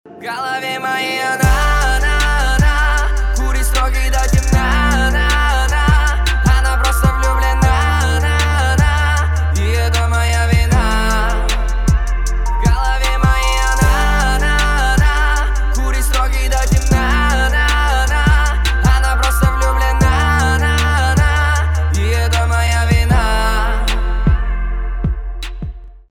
• Качество: 320, Stereo
поп
лирика
Хип-хоп
грустные